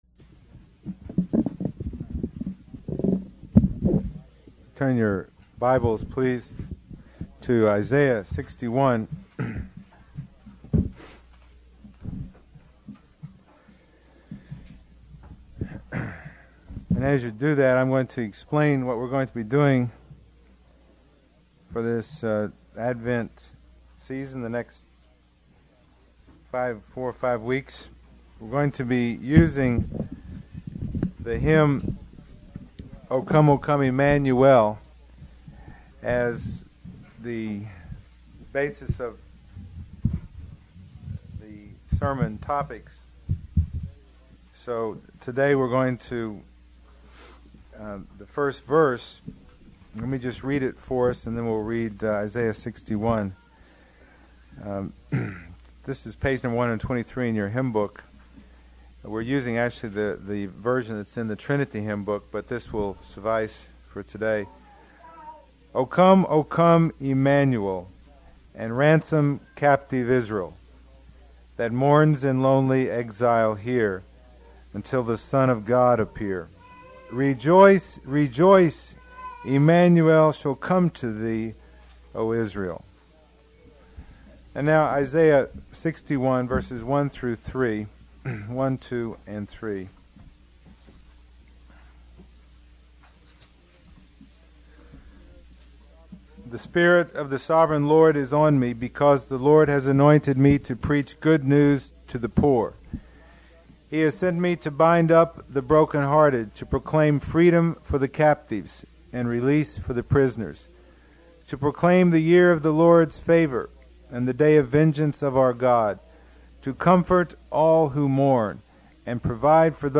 No Series Passage: Isaiah 61:1-3 Service Type: Sunday Morning %todo_render% « Stewardship